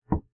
default_wood_1.ogg